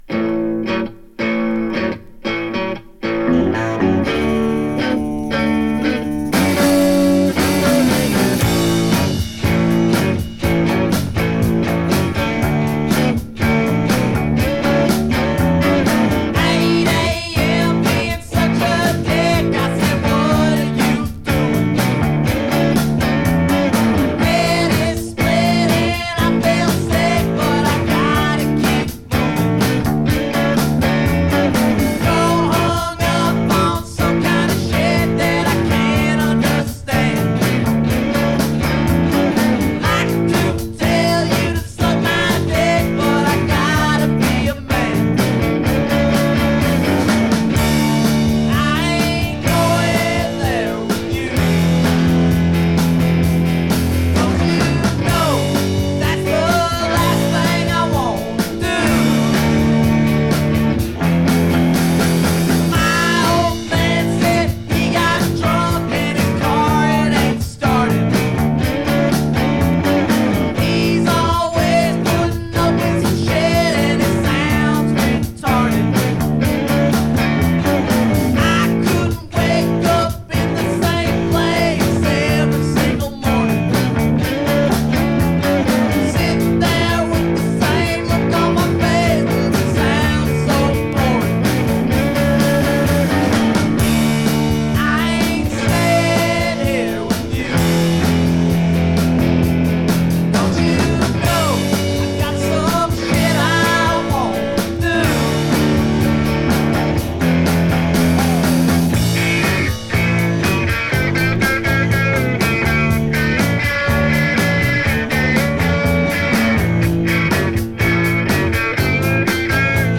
scuzzy bar rock